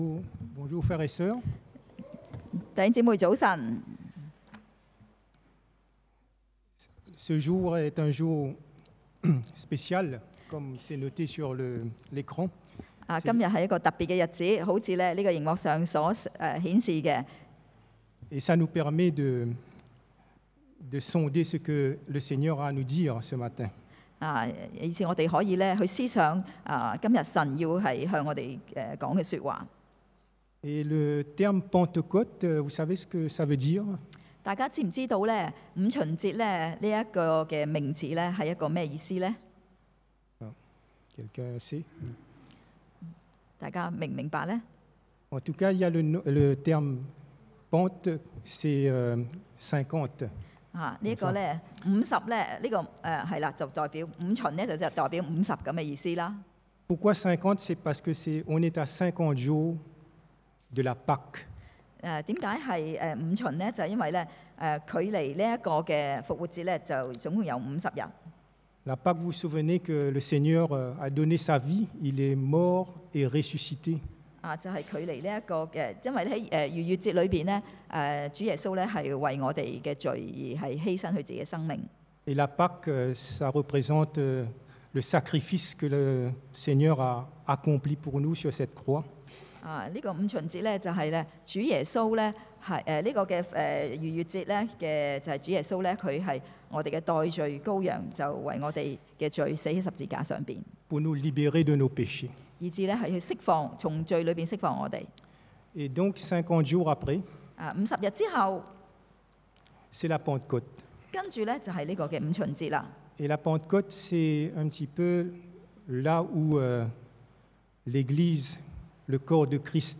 Pentecôte 五旬节 – Culte du dimanche